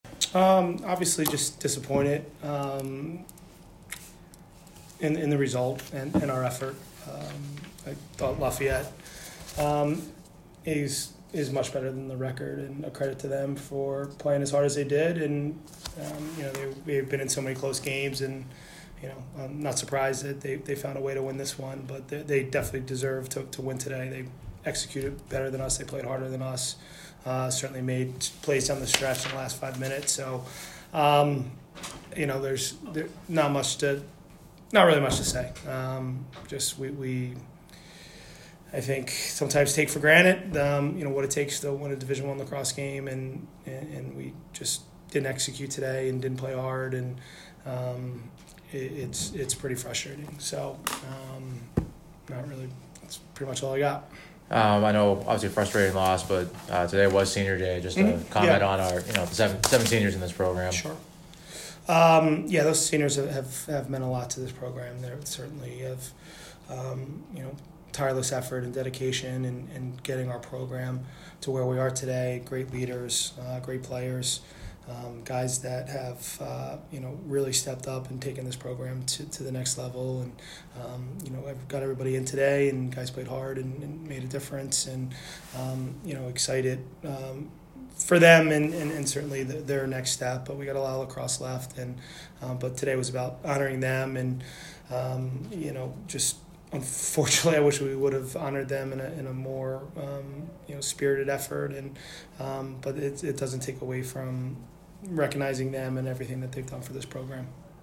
Lafayette Postgame Interview